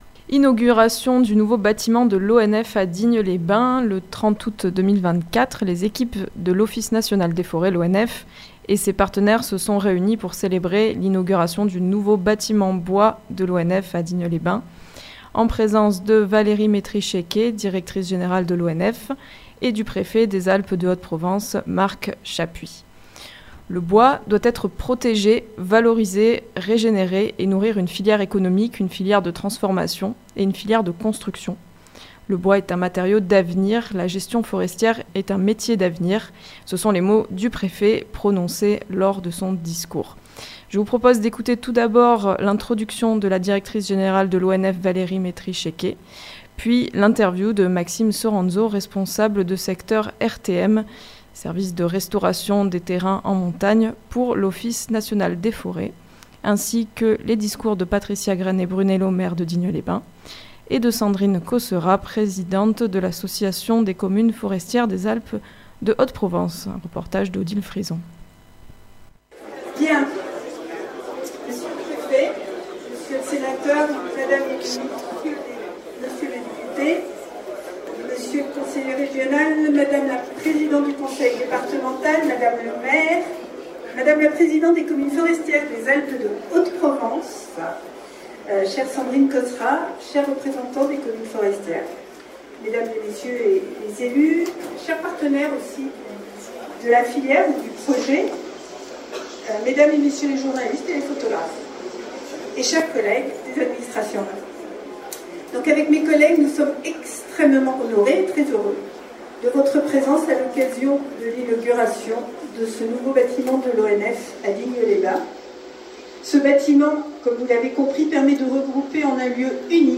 Ce sont les mots du Préfet prononcés lors de son discours.